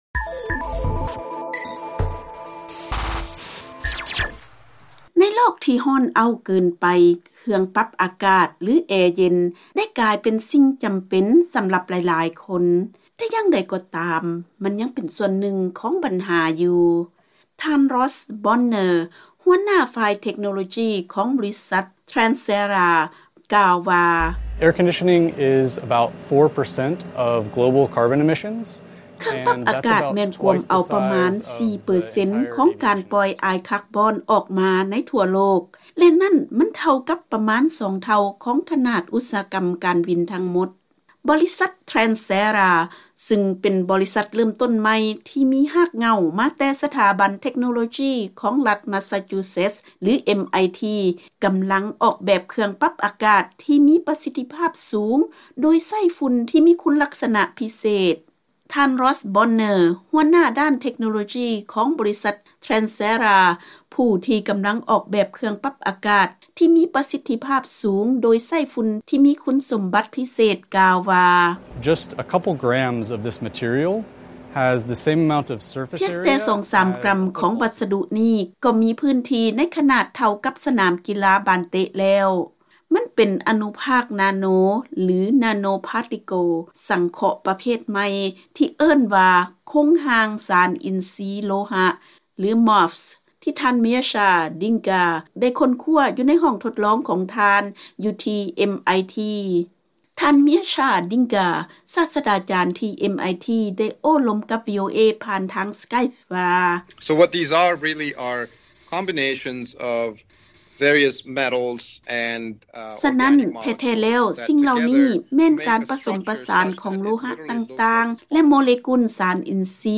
ເຊີນຟັງລາຍງານການໃຊ້ຝຸ່ນເພື່ອດູດຊຶມເອົາຄວາມຊຸ່ມທີ່ເຂົ້າໄປໃນແອເຢັນສາມາດຊ່ວຍເພີ້ມປະສິດທິພາບຂອງແອເຢັນໄດ້